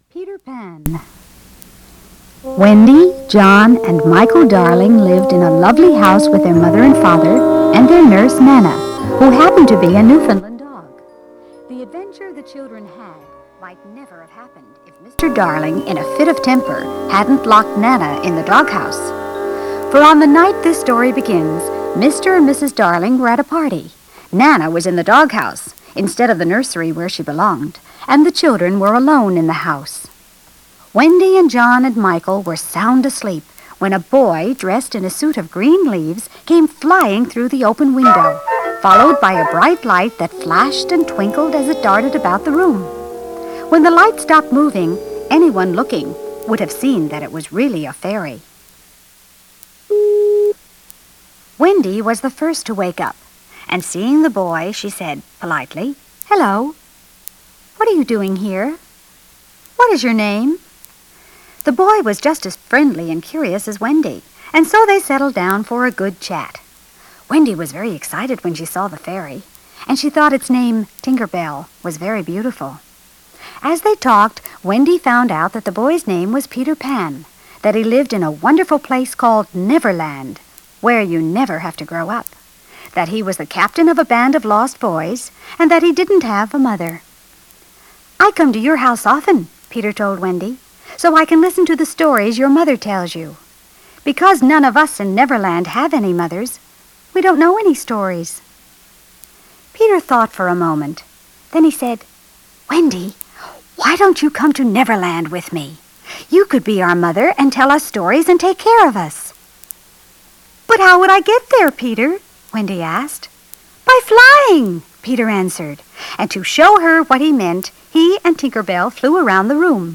DOWNLOAD AUDIO NOVEL